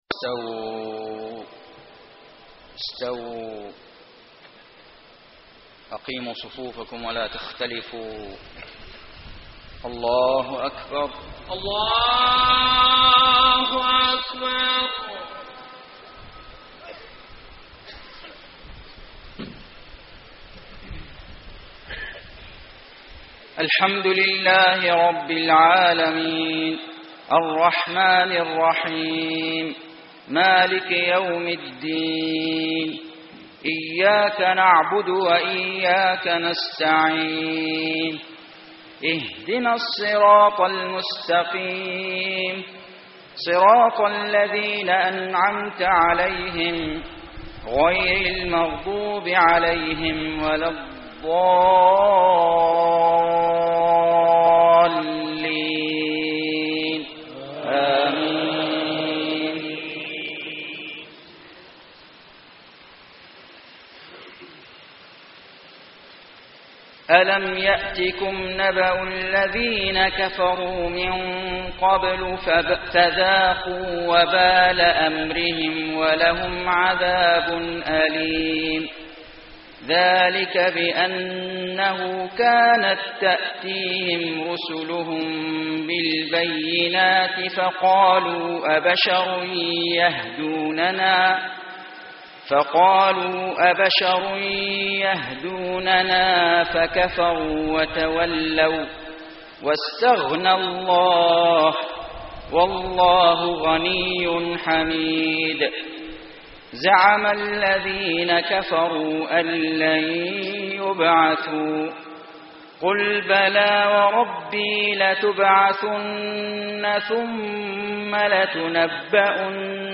صلاة المغرب 9-6-1434 من سورة التغابن > 1434 🕋 > الفروض - تلاوات الحرمين